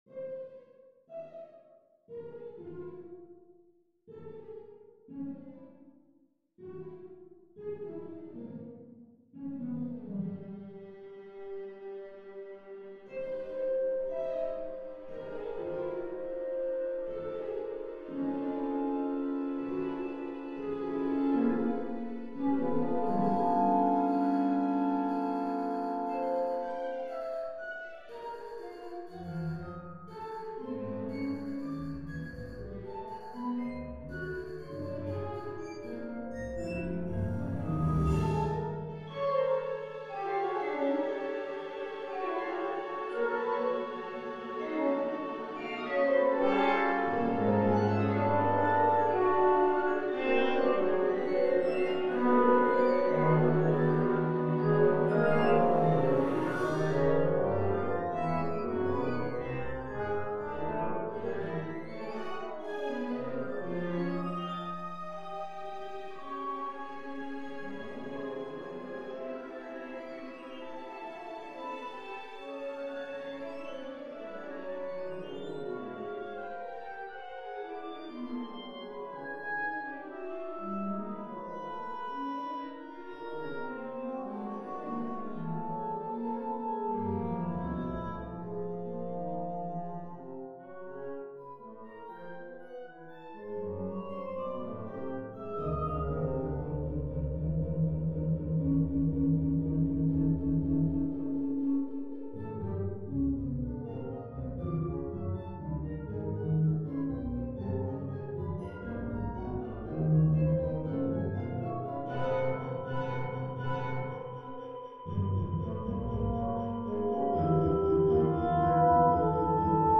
Allegro leggiero